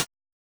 Index of /musicradar/ultimate-hihat-samples/Hits/ElectroHat C
UHH_ElectroHatC_Hit-27.wav